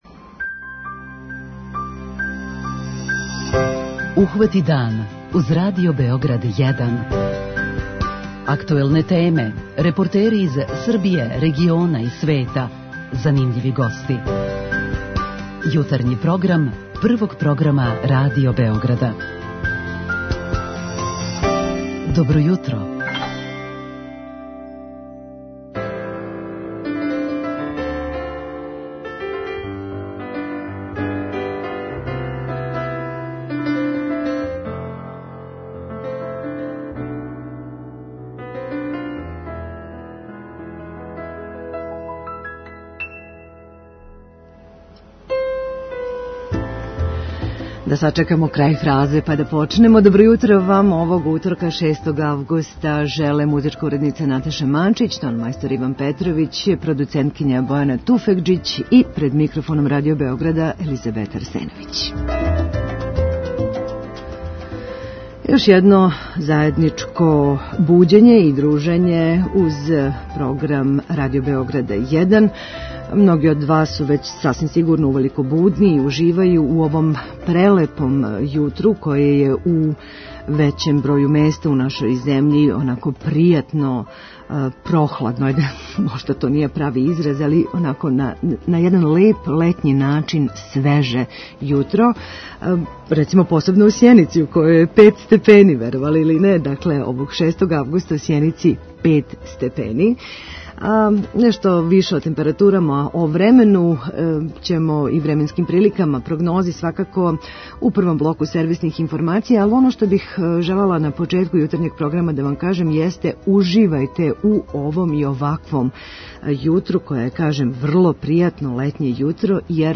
И данас у Јутарњем програму све најважније вести и сервисне информације, занимљивости и обиље добре музике!